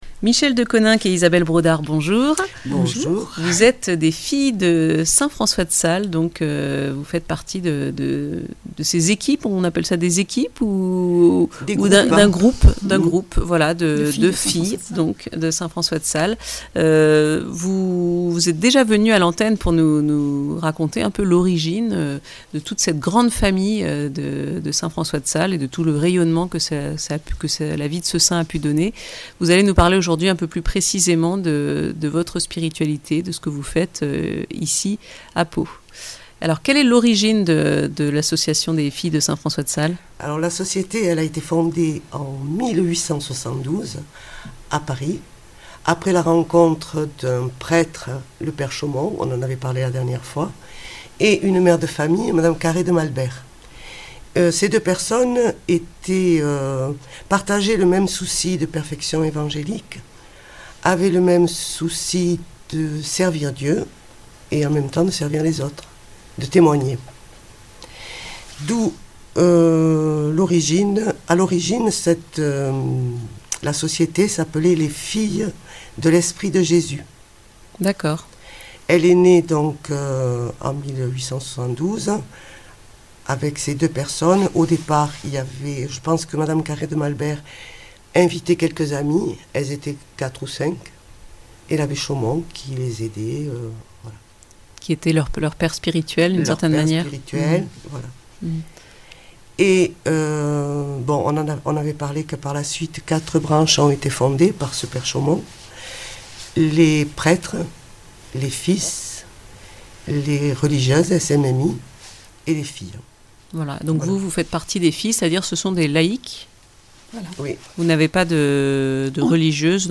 A l’occasion de la fête de Saint François de Sales le 24 janvier, deux Filles de Saint François de Sales de Pau nous parlent de la spiritualité de cette Société fondée par l’abbé Henri Chaumont et Madame Carré de Malberg.
Interviews et reportages
Rencontre avec deux Filles de St François de Sales